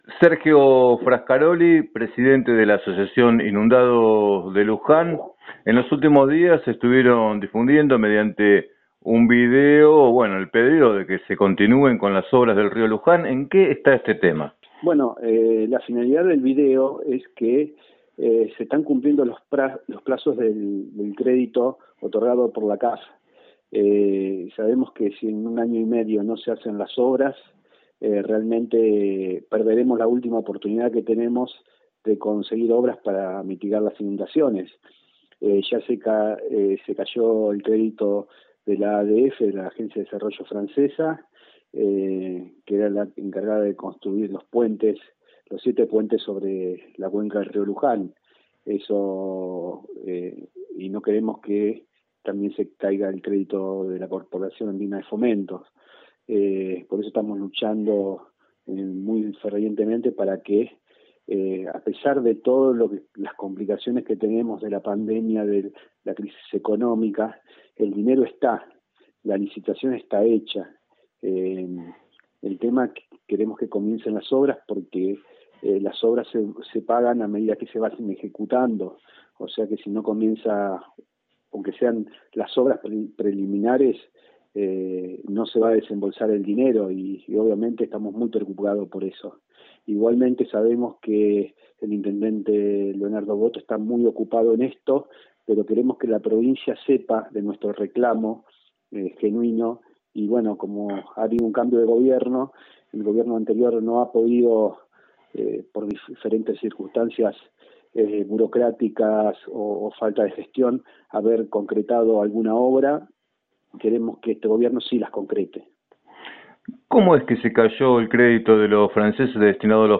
En diálogo con Radio Líder 97.7